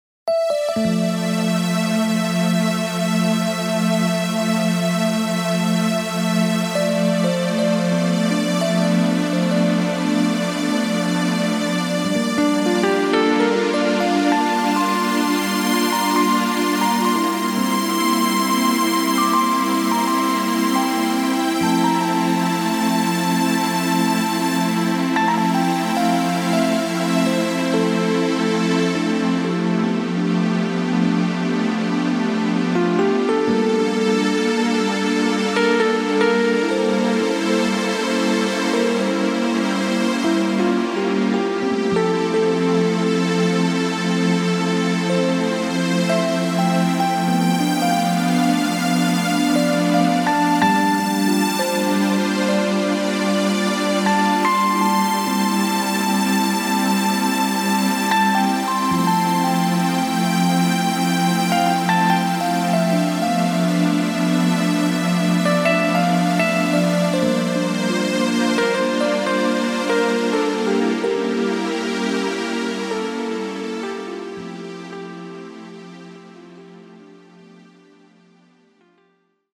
tout en suavité, en douceur.